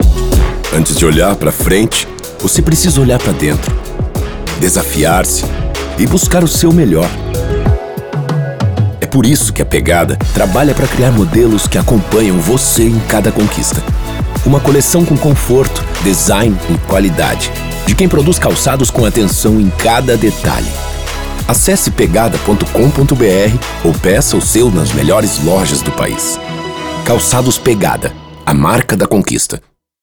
Masculino
Voz Padrão - Grave 00:30
Voz grave coloquial.